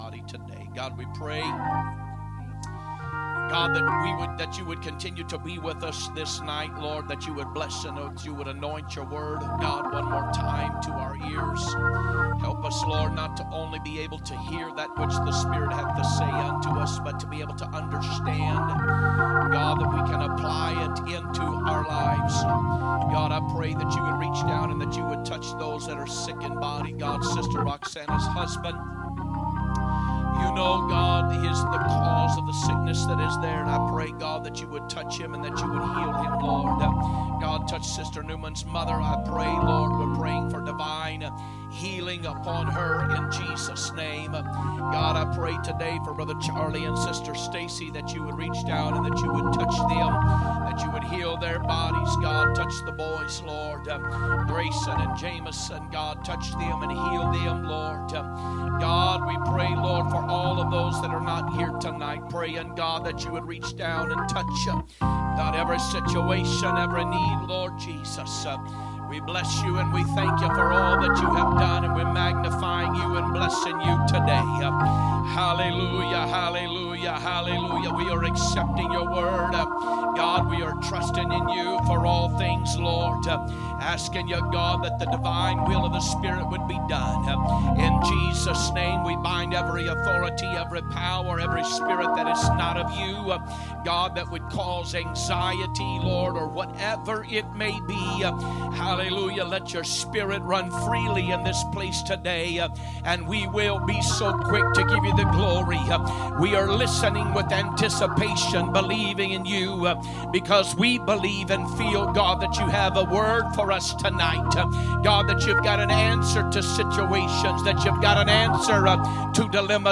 Wednesday Service